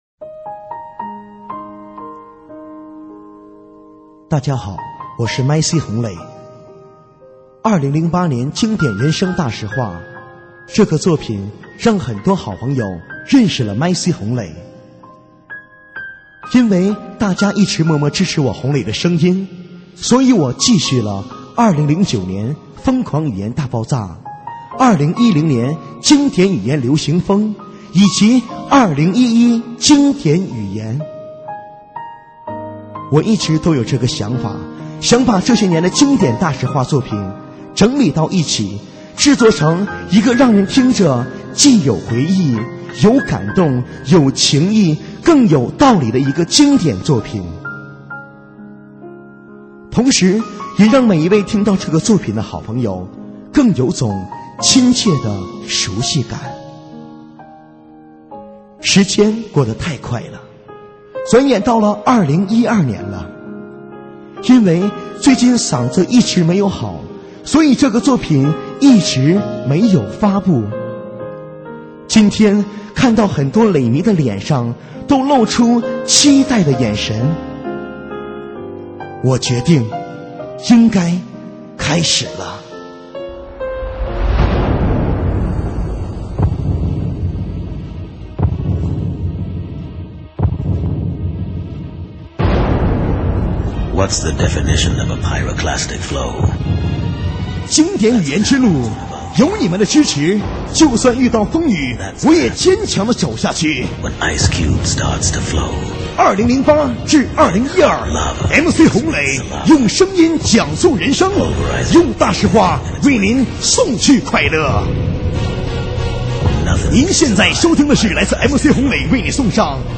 现场串烧